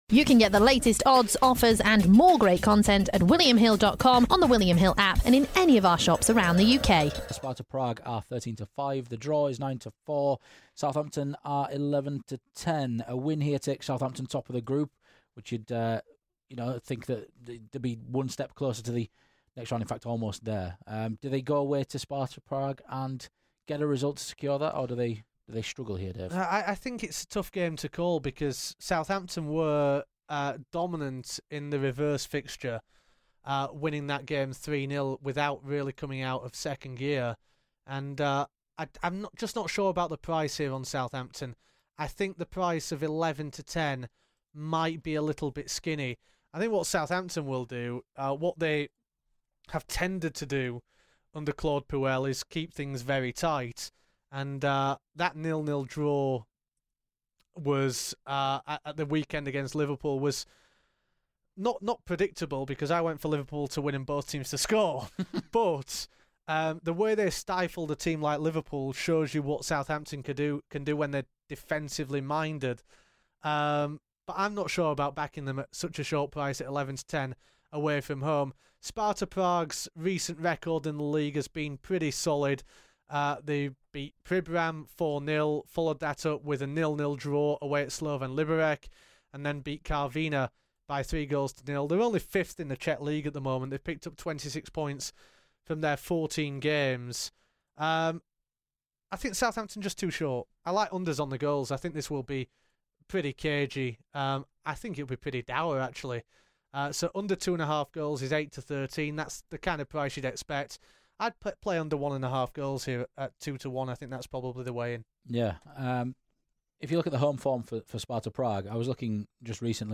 Listen. Europa League Match Preview.
Sparta Prague v Southampton. This is an excerpt from The Punt podcast.